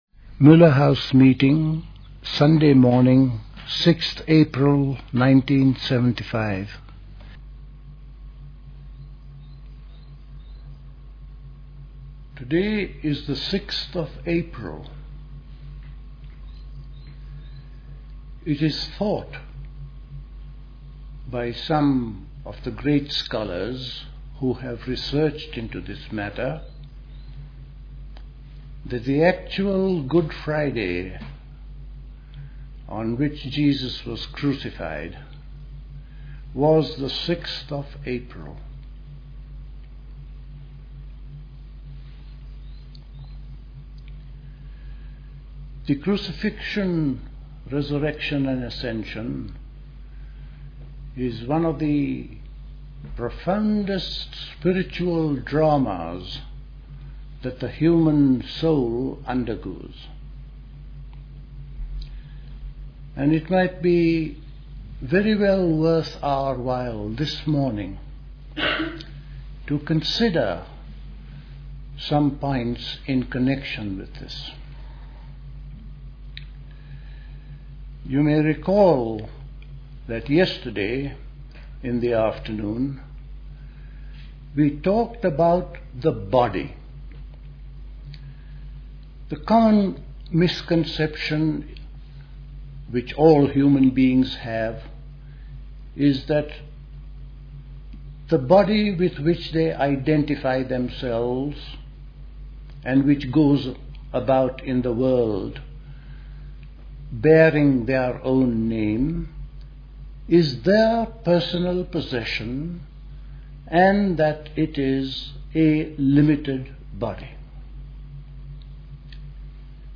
Recorded at the 1975 Elmau Spring School.